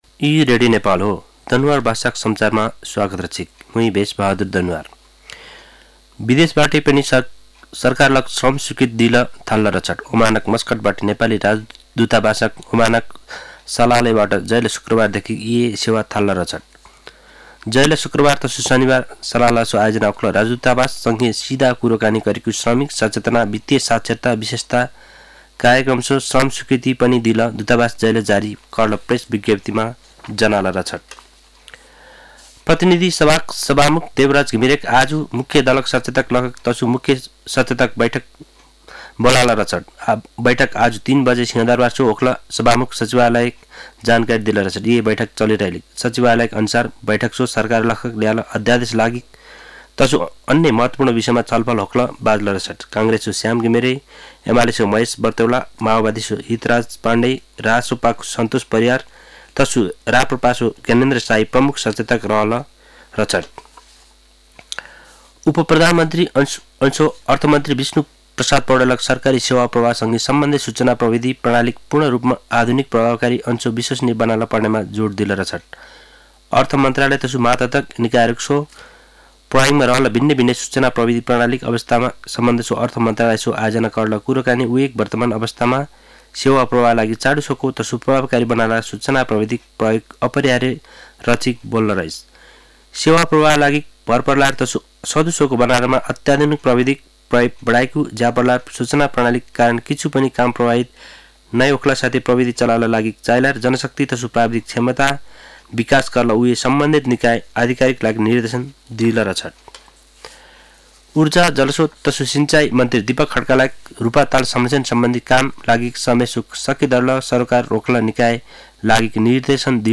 दनुवार भाषामा समाचार : २४ माघ , २०८१
Danuwar-news-1-1.mp3